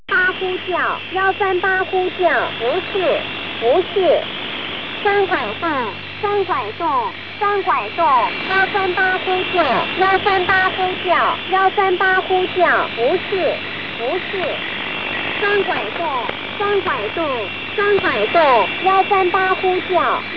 Listen to the station Chinese Numbers (ENIGMA V16) Recorded Spring, 1995 at 2000 on 11028 kHz